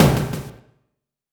sound_attack_1.wav